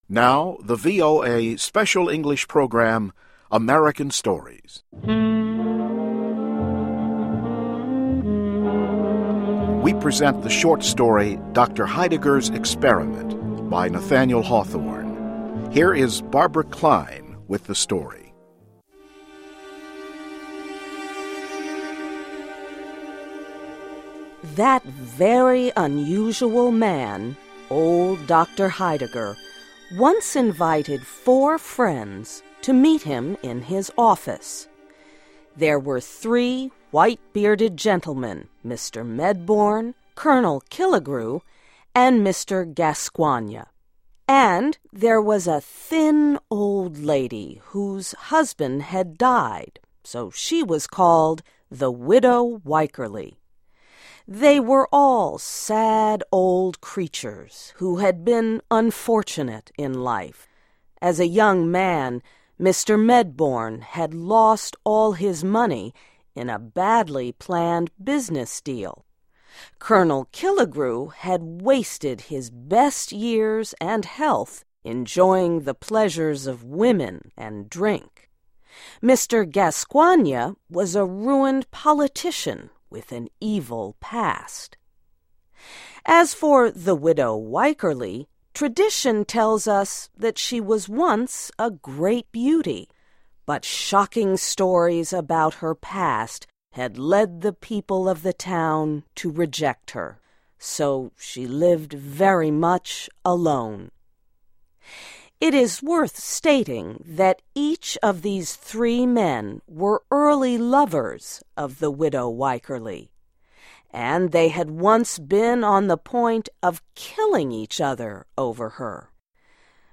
Short Story: ‘Doctor Heidegger’s Experiment’ by Nathaniel Hawthorne